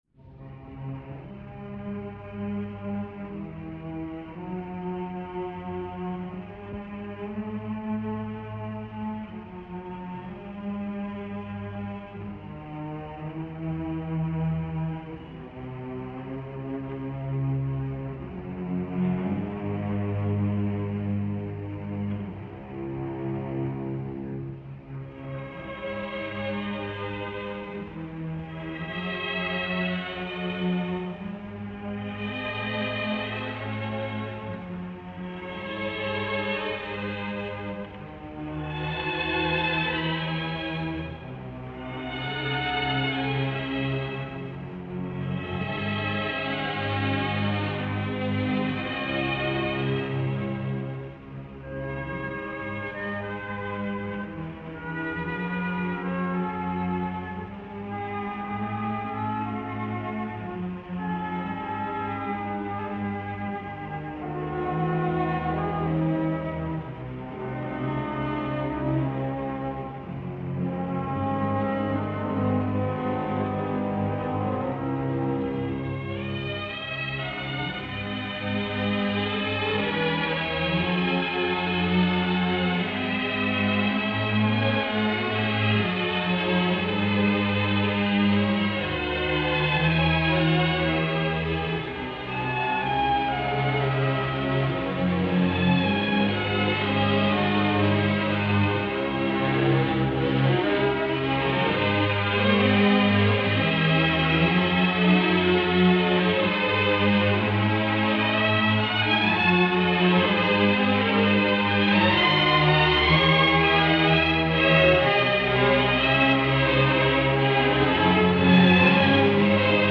Passacaglia & Fugue in C minor, BWV 582